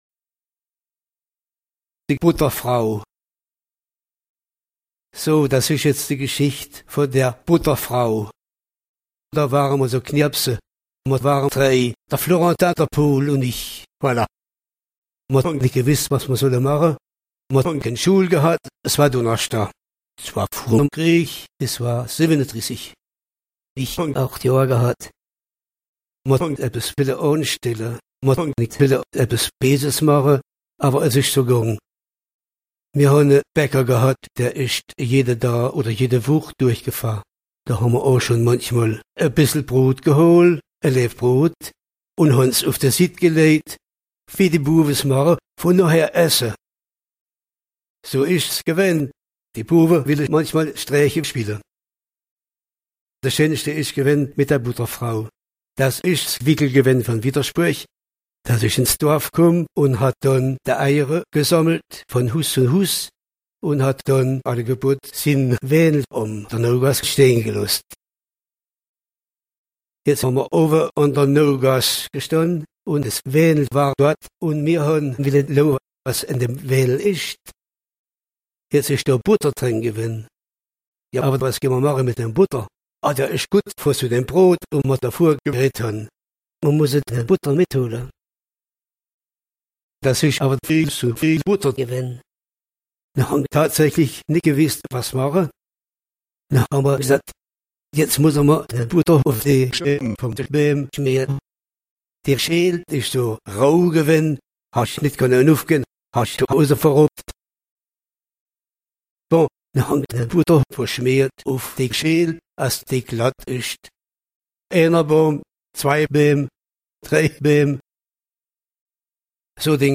Contes et chansons en dialecte roman ou allemand, enregistrés dans les communes de Bettring, Bouquenom, Honskirch, Vittersbourg, Insming, Réning, Léning, Francaltroff, Rodalbe, Virming, Bermering.
Honskirch